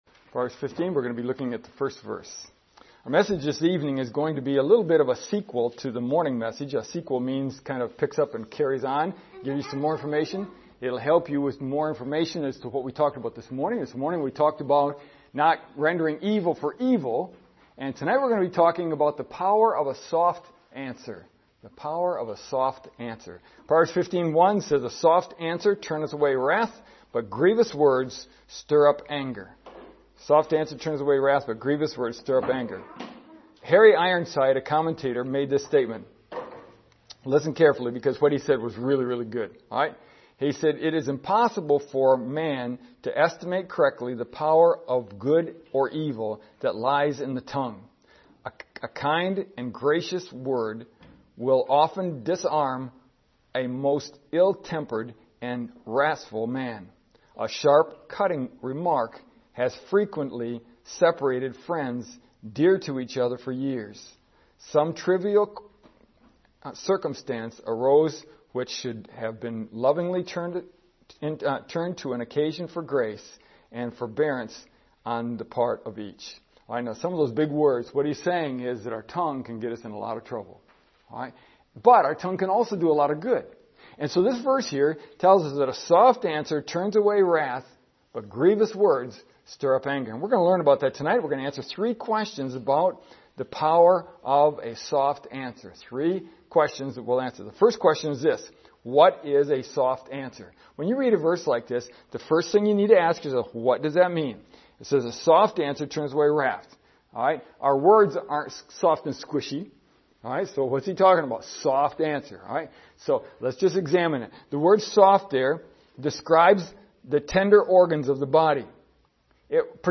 Our message this evening is somewhat of a sequel to the morning message.